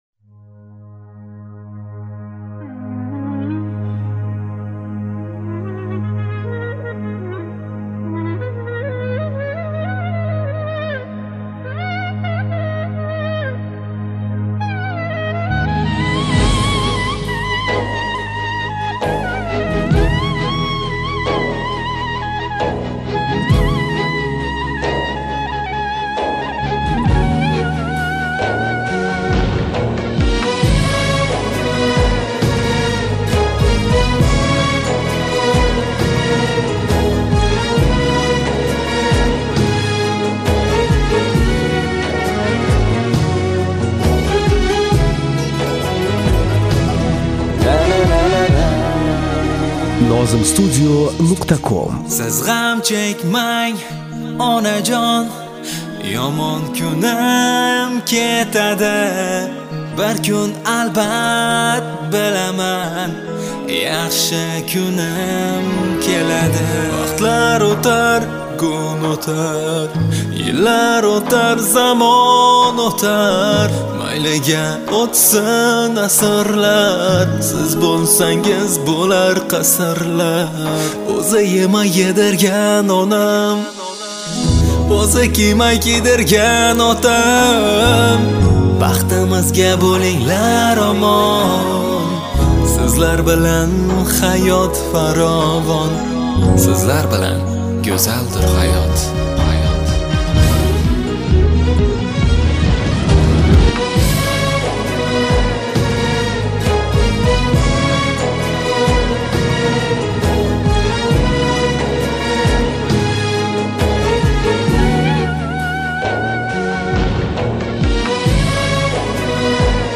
UZBEK MUSIC [7816]
minus